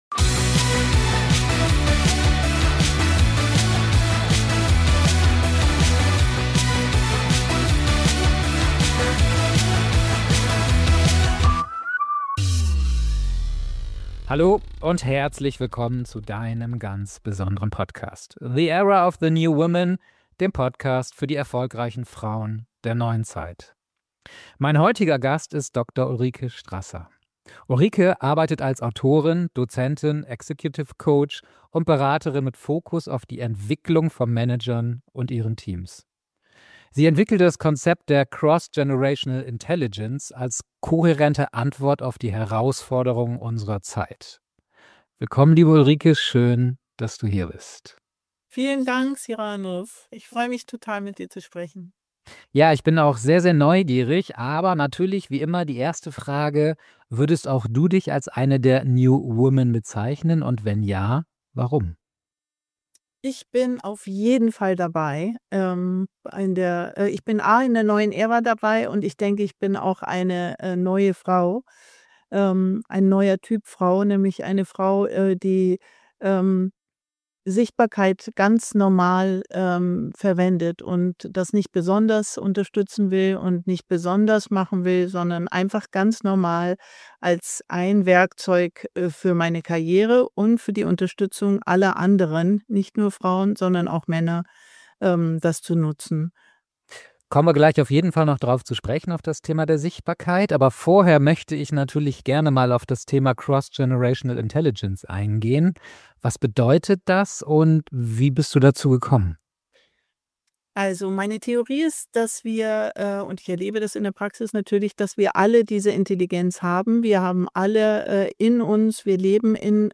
Ein Gespräch über Generationen, Karriere, Systemwandel – und darüber, warum Frauen nicht repariert werden müssen, sondern einfach gesehen werden sollten.